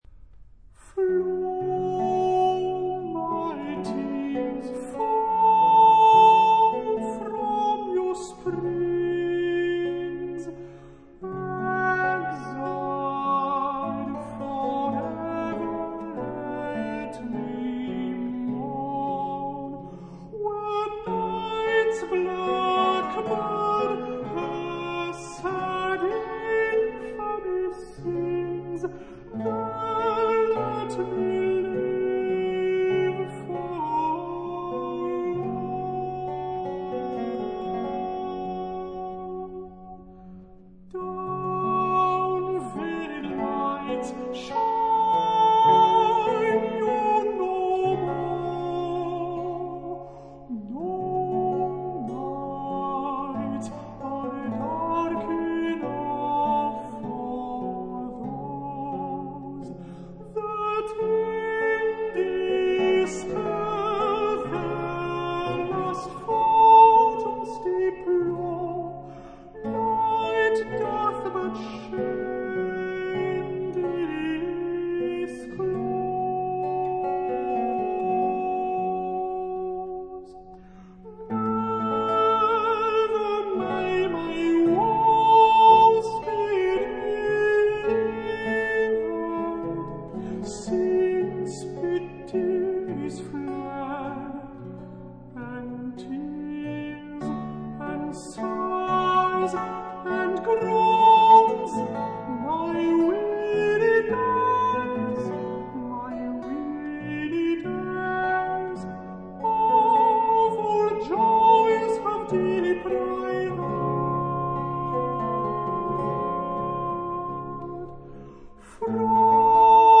Dowland／魯特琴歌曲集